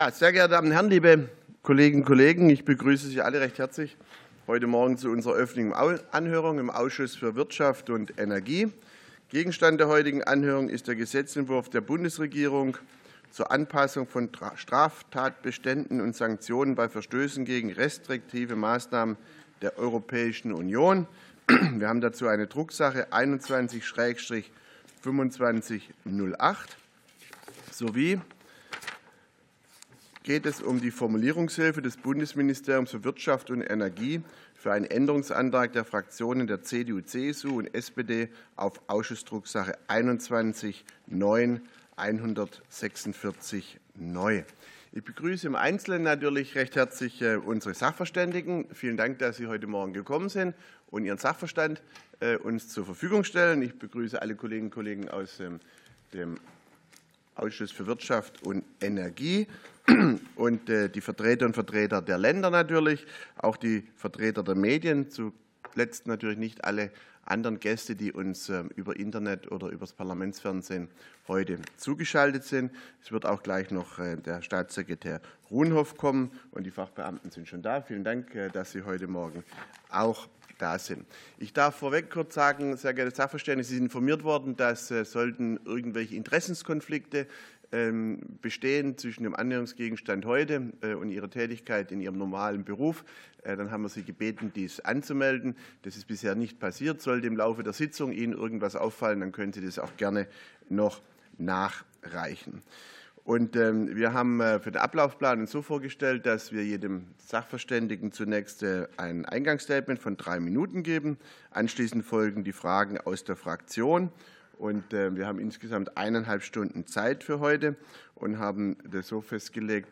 Anhörung des Ausschusses für Wirtschaft und Energie